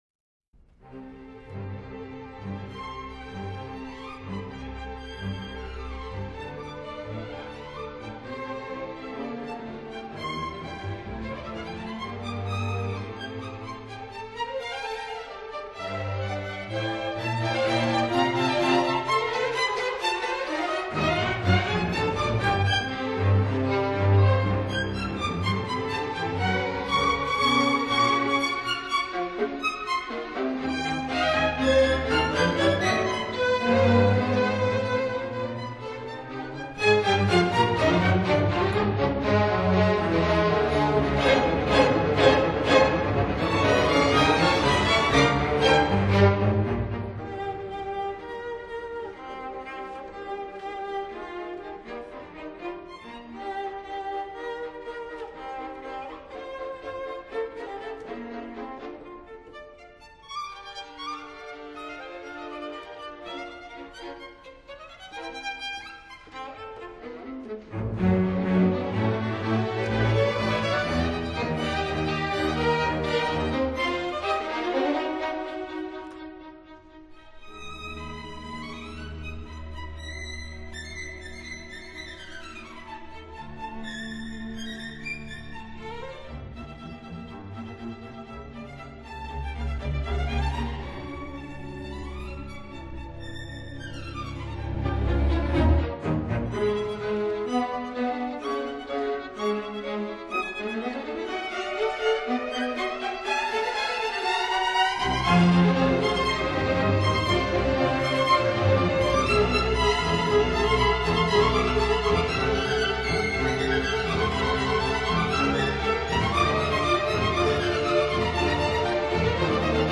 分辑：CD12-CD13  室内交响曲